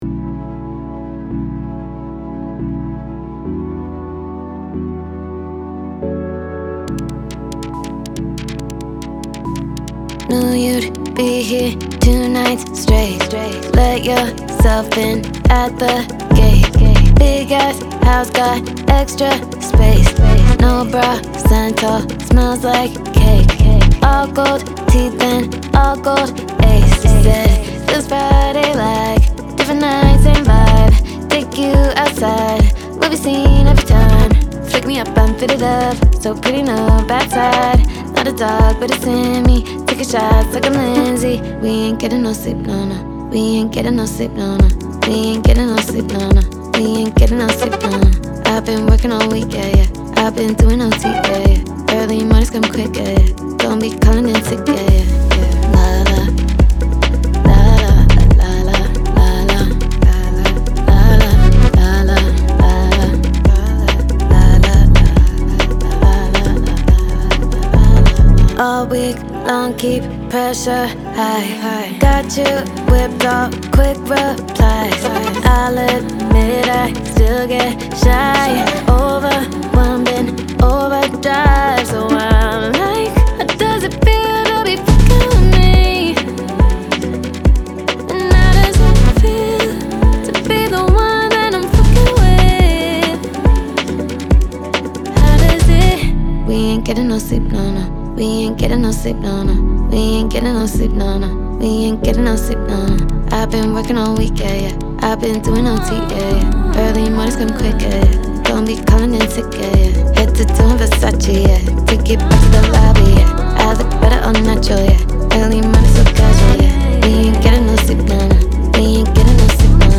• Жанр: R&B